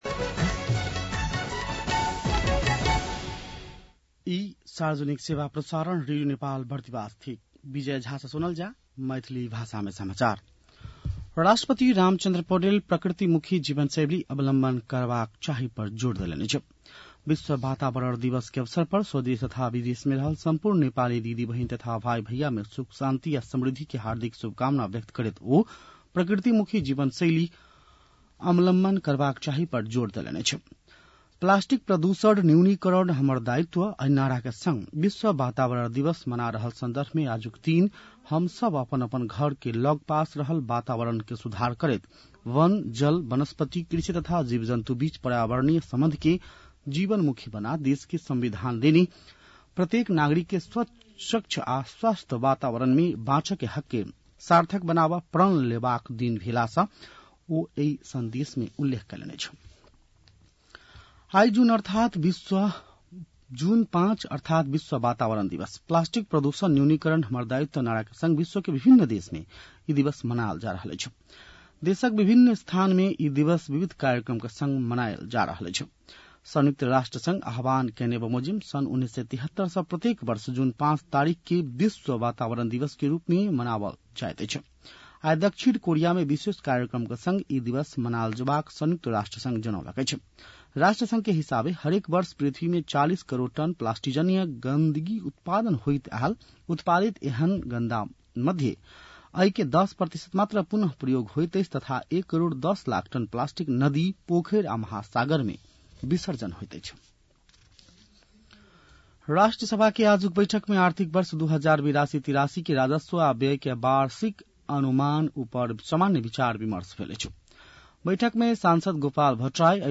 मैथिली भाषामा समाचार : २२ जेठ , २०८२
Maithali-news-2-22.mp3